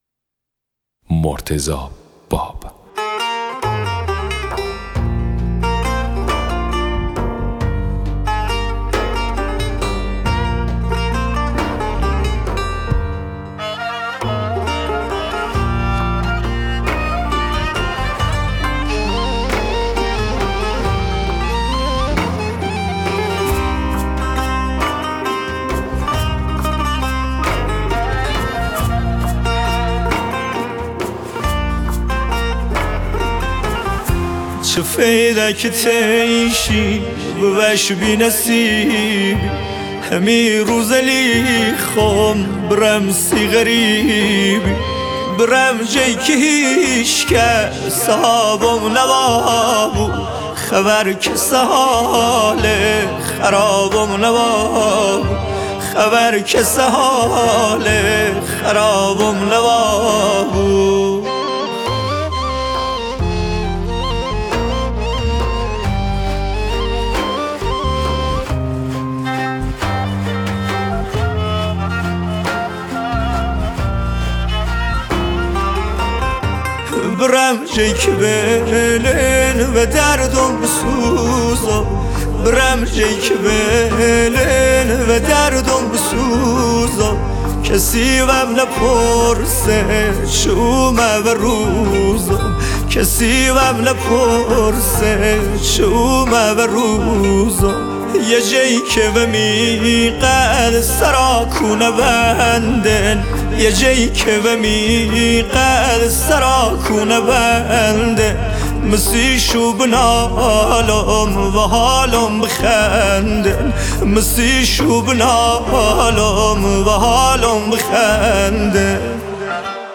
موزیک لری